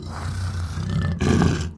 spawners_mobs_uruk_hai_neutral.ogg